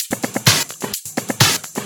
Index of /VEE/VEE Electro Loops 128 BPM
VEE Electro Loop 183.wav